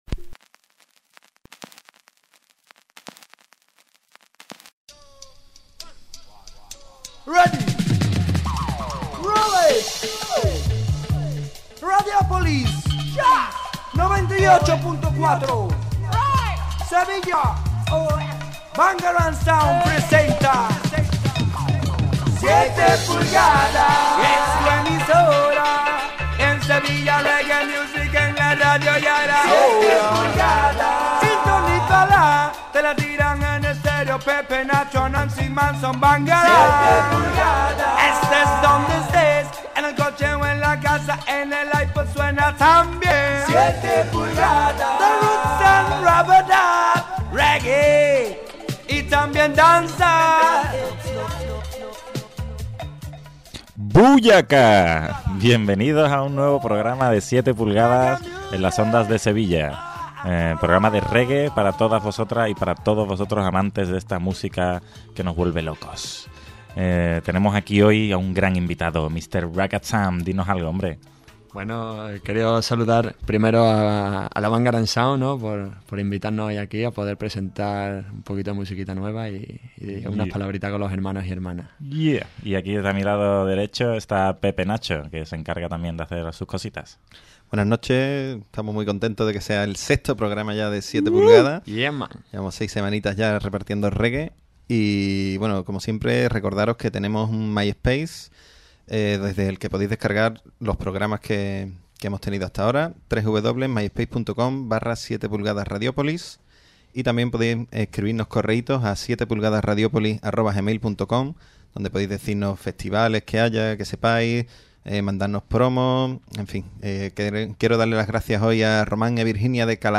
7PULGADAS es un programa dedicado a la música reggae y dancehall dirigido y presentado por la BANGARANG SOUND